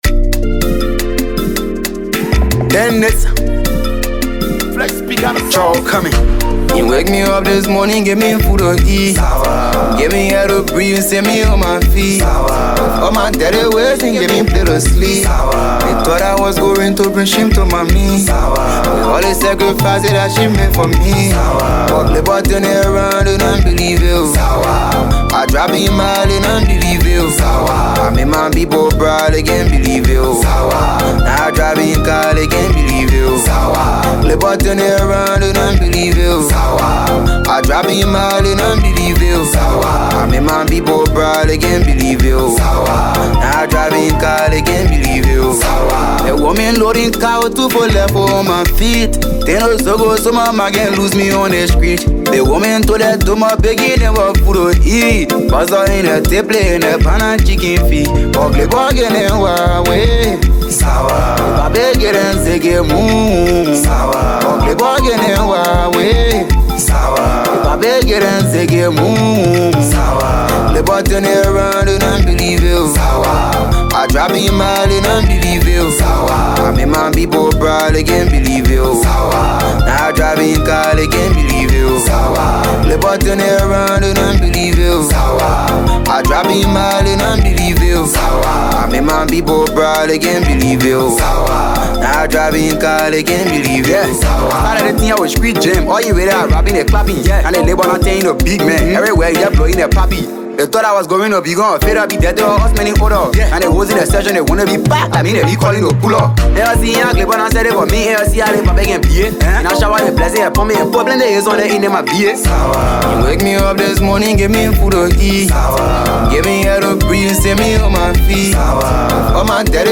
is a soul-stirring anthem